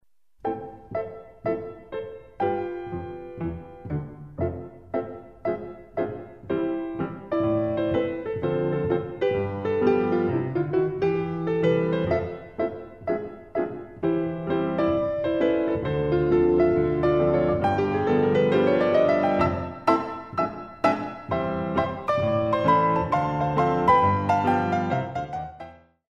Compositions for Ballet Class
Some very Classical plus some jazz - ragtime rhythms
The CD is beautifully recorded on a Steinway piano.
Degages a terre